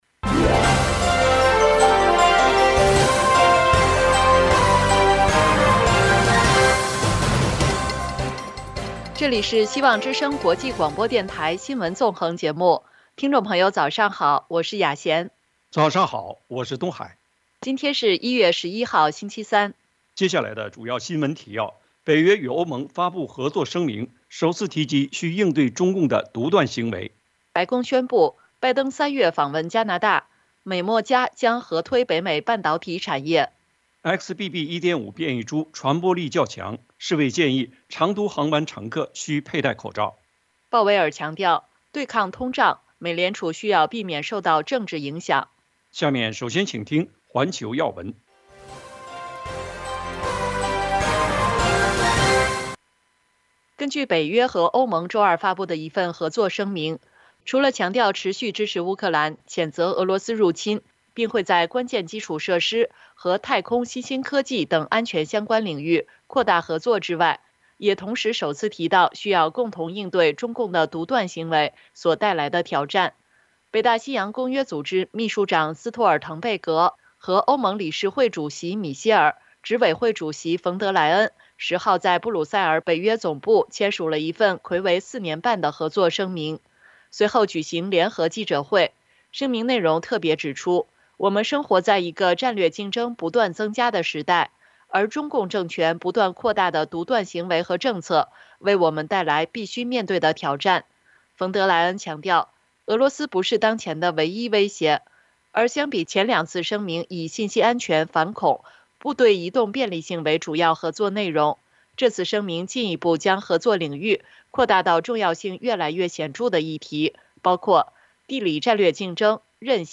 众议院高票通过“中国委员会” 两党共同应对中共挑战【晨间新闻】